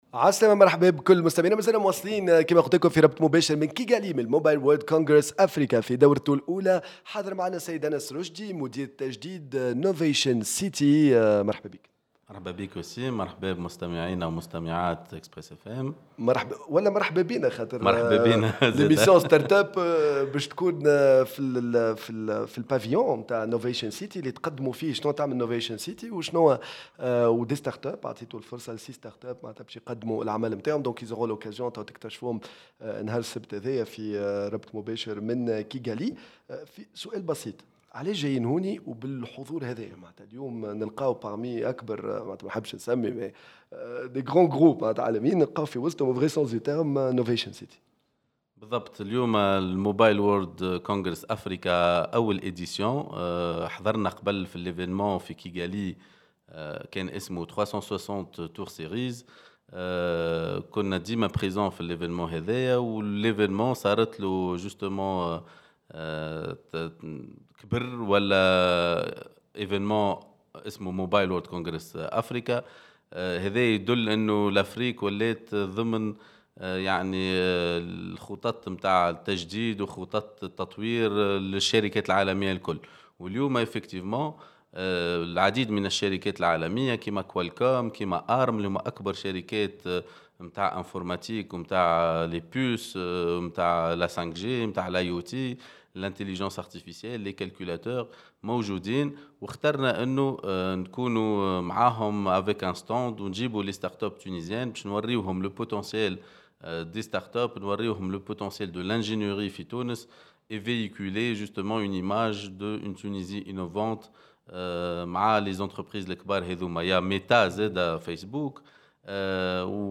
Comment transformer l'industrie ? En direct du Mobile World Congress Africa (Rwanda)